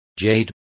Also find out how rocin is pronounced correctly.